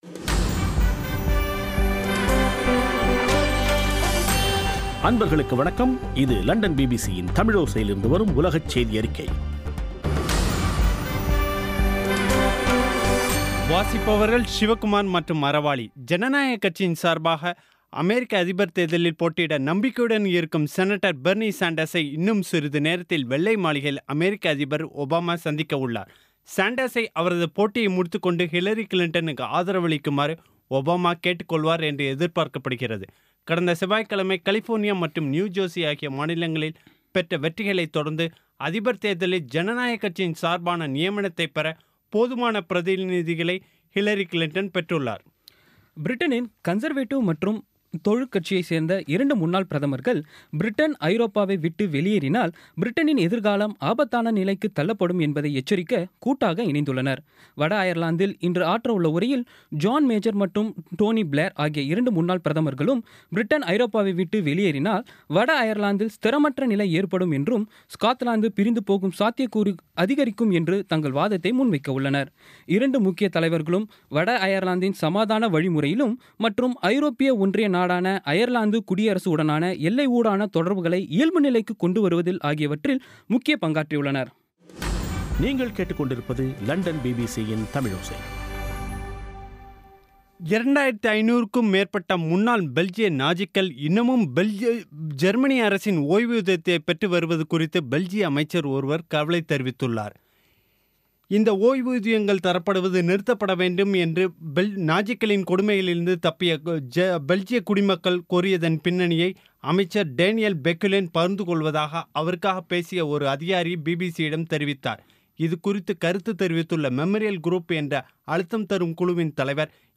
இன்றைய (ஜூன் 9ம் தேதி ) பிபிசி தமிழோசை செய்தியறிக்கை